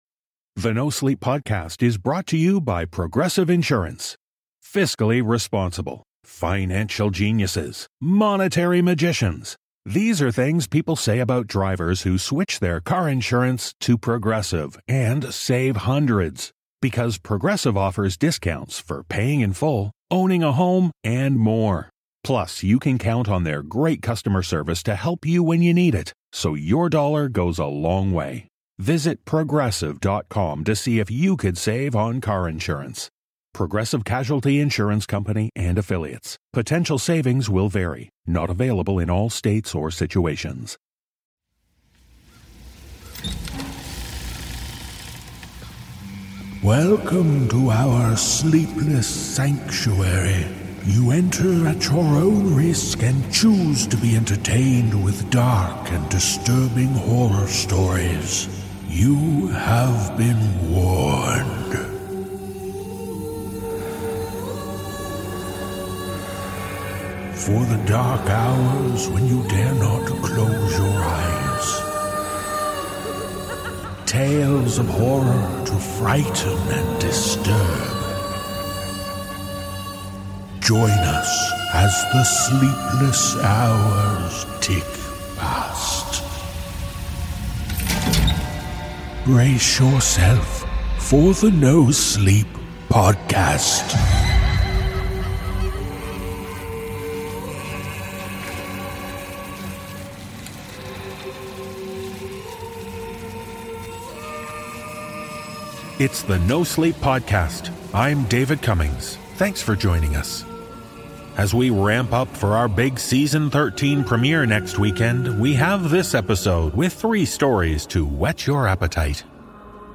Click here to learn more about the voice actors on The NoSleep Podcast